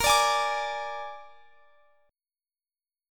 Bb7 Chord
Listen to Bb7 strummed